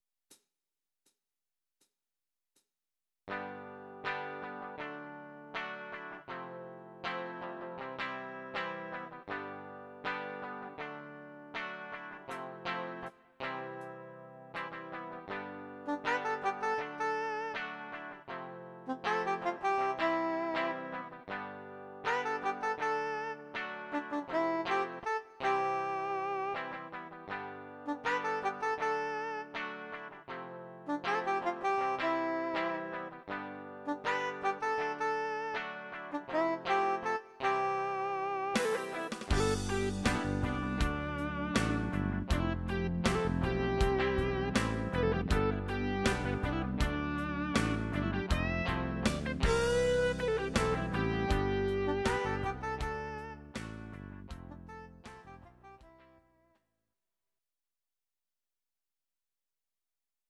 These are MP3 versions of our MIDI file catalogue.
Please note: no vocals and no karaoke included.
Your-Mix: Pop (21635)